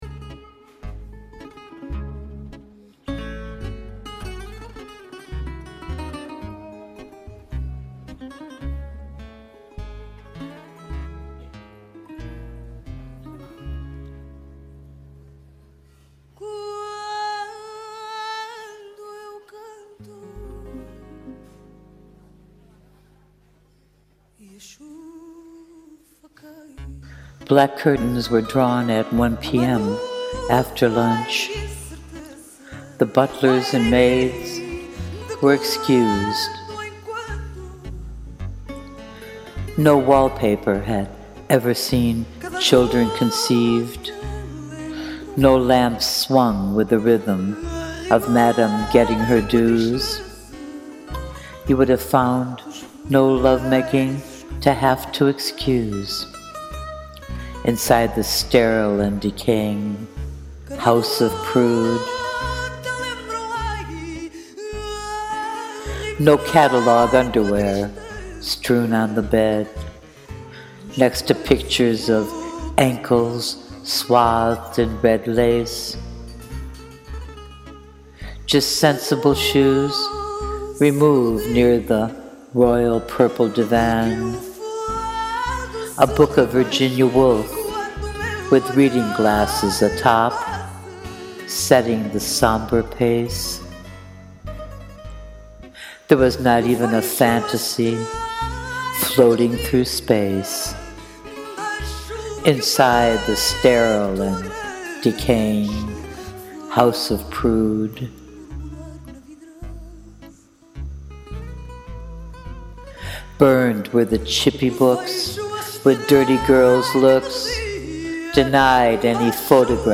I love the ambience you set with the music and your tone.
Love the amusing, kinda cheeky tone of this one.
I loved the music and I adored the verbal poetry.